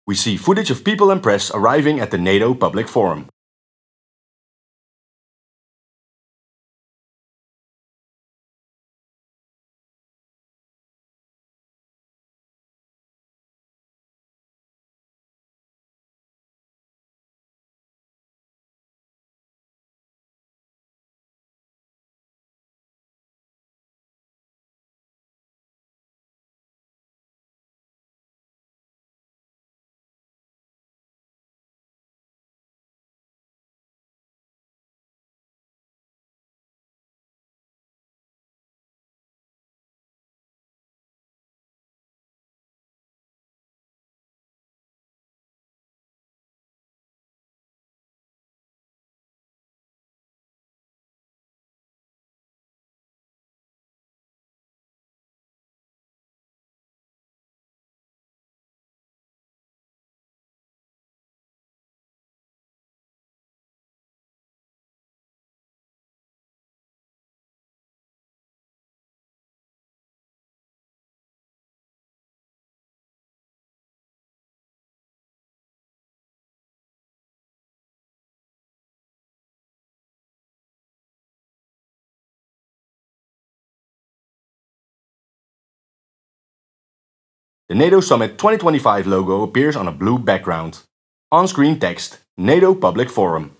*Exciting music plays*
*Applause*
*Music plays*